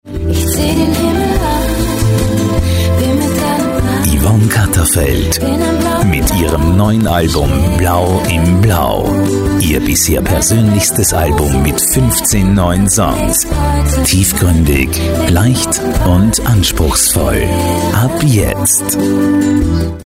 Freundliche tiefe Männerstimme, akzentfrei und hochdeutsch.
Music Promos
Promo Soft